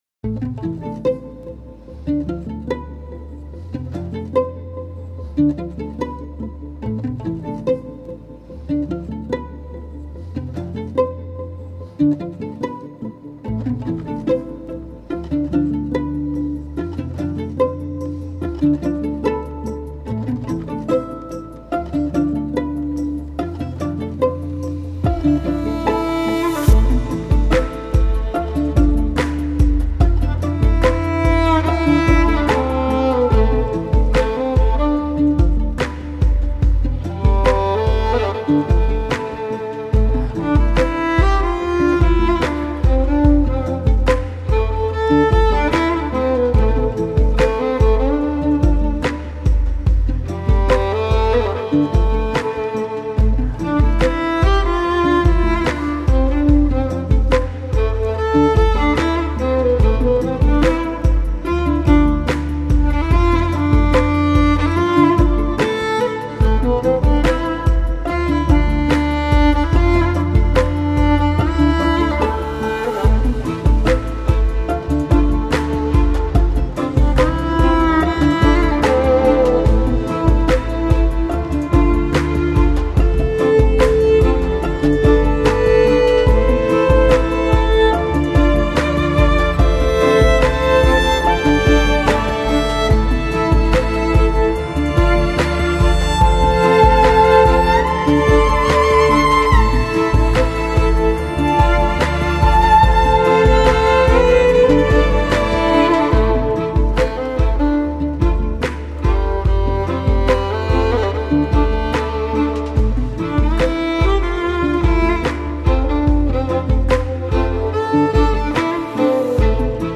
• Classically trained violinist specialising in live looping
• A unique combination of violin and beatboxing
• Covers (Loop Pedal)
Violin, Loop pedals, Beat boxing